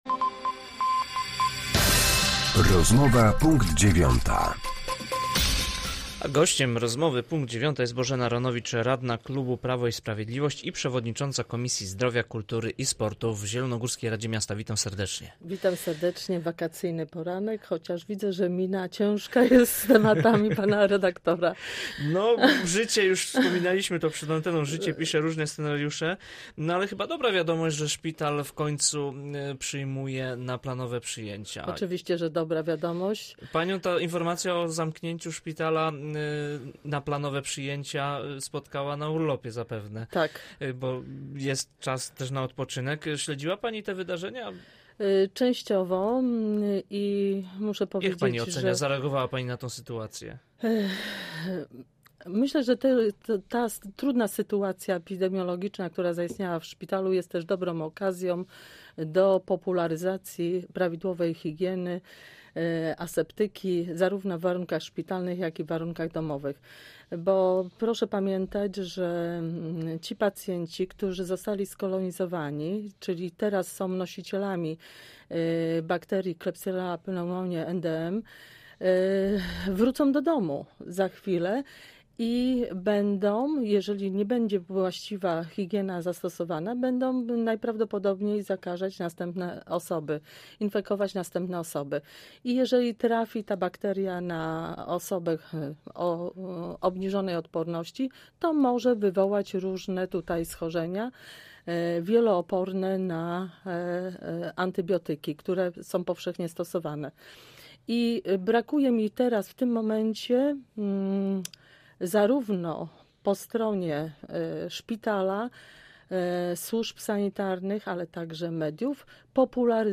Z radną klubu Prawo i Sprawiedliwość rozmawiał